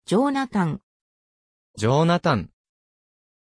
Pronunciación de Joonatan
pronunciation-joonatan-ja.mp3